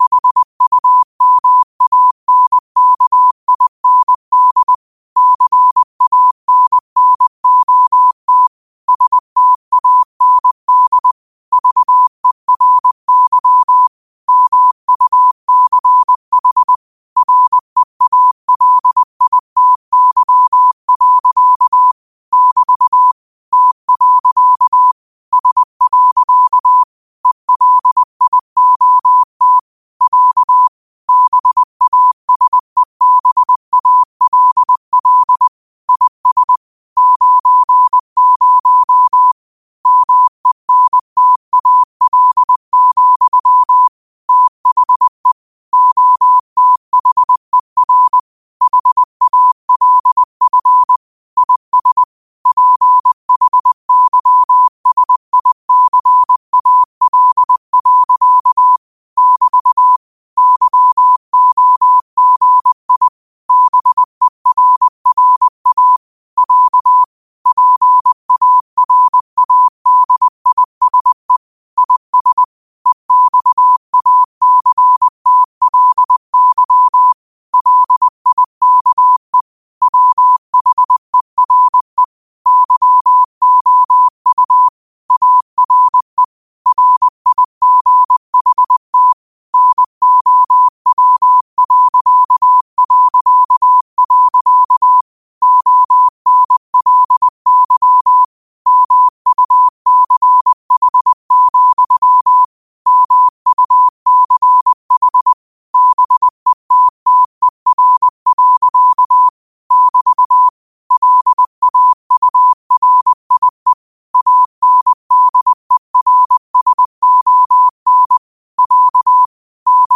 Quotes for Thu, 14 Aug 2025 in Morse Code at 20 words per minute.